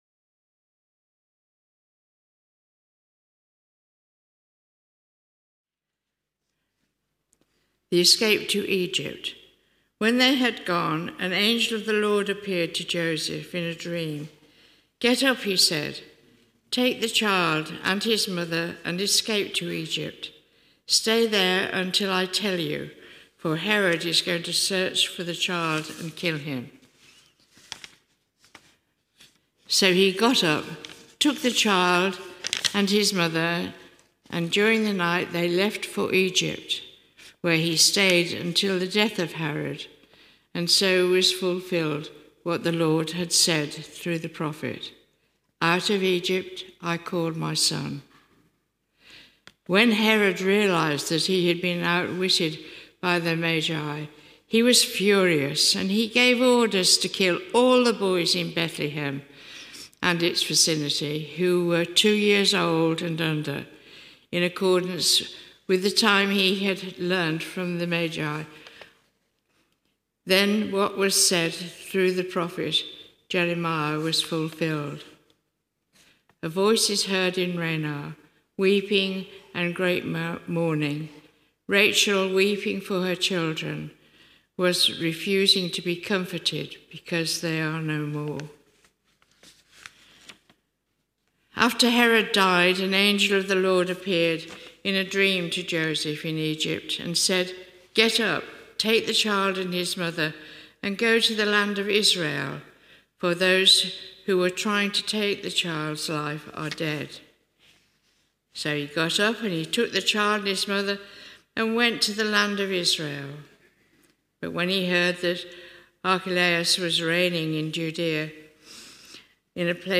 At this morning’s service